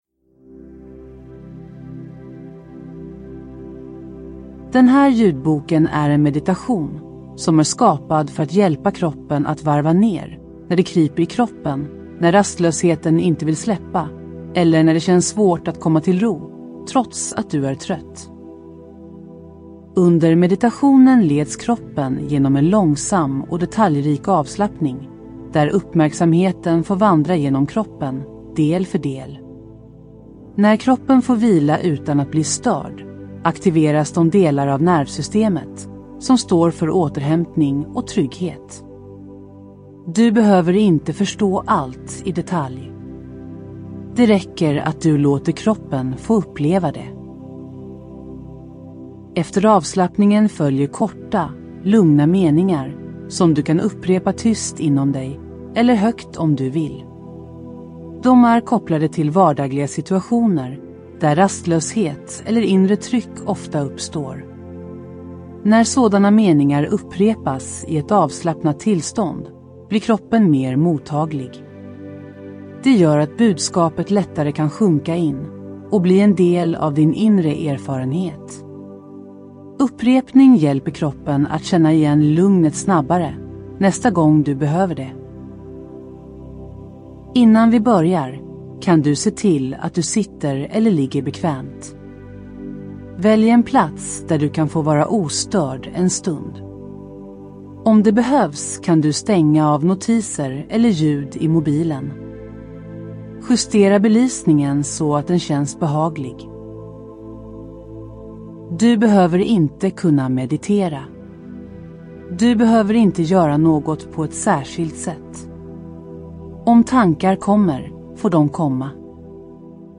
En guidad meditation för rastlöshet och inre spänning
• En lugn introduktion som avdramatiserar rastlöshet